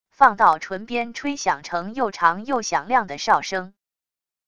放到唇边吹响成又长又响亮的哨声wav音频